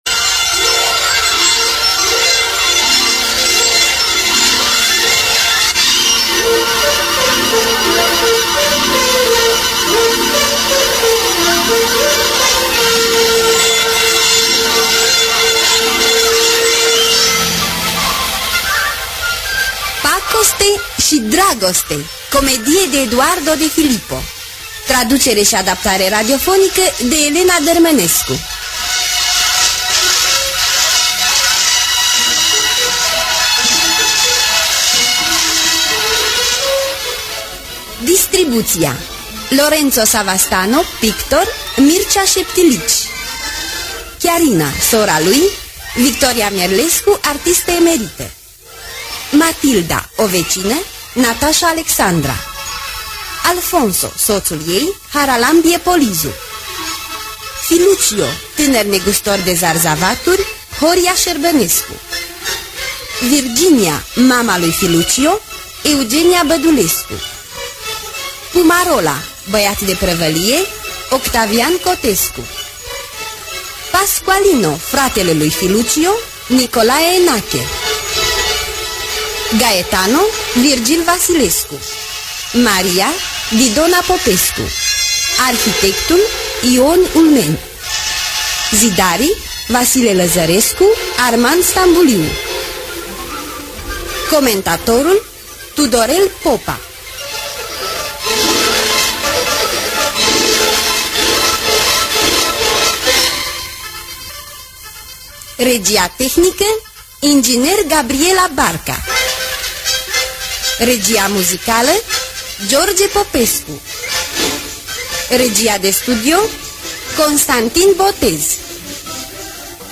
„Pacoste şi dragoste” de Eduardo de Filippo – Teatru Radiofonic Online